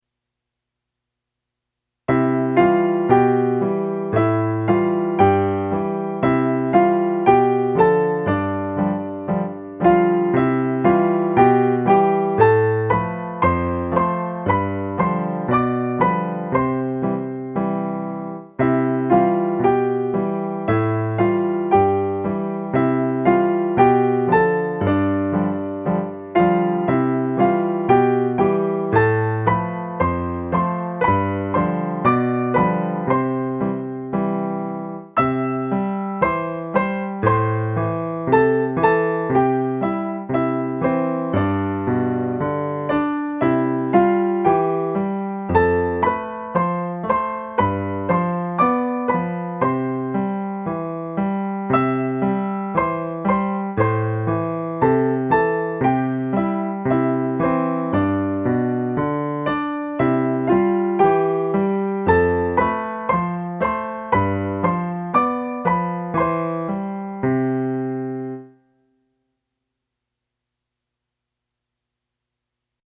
for easy playing by little hands
plus 18 more classic cowboy tunes.